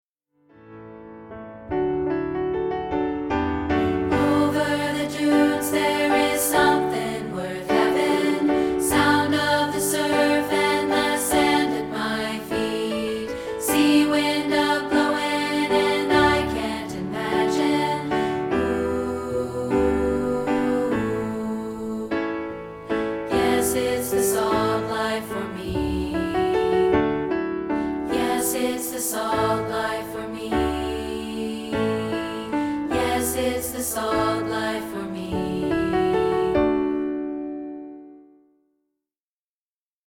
including this rehearsal track for part 3B.